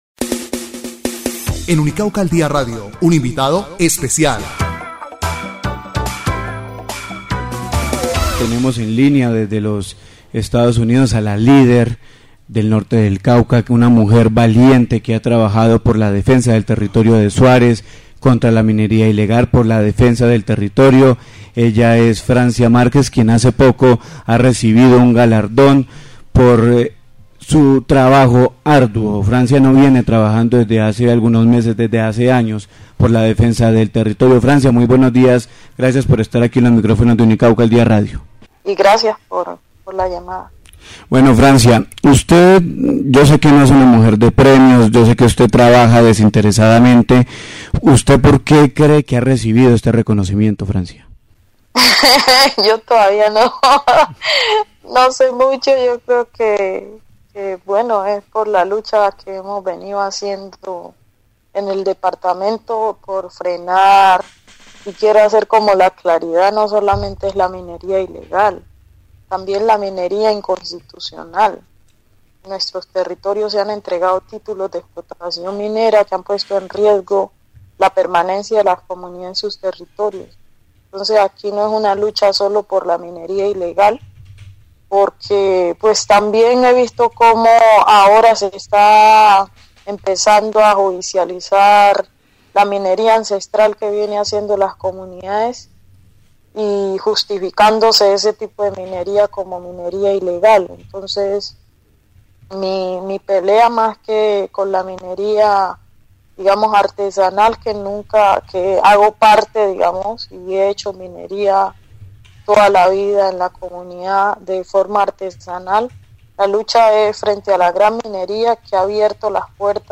Ver más >> Entrevista Francia Márquez - Unicauca al Día radio